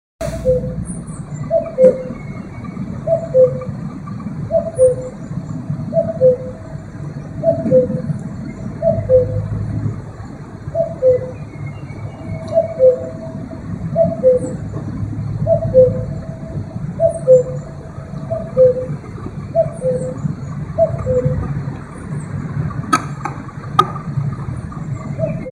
Kuckuck.mp3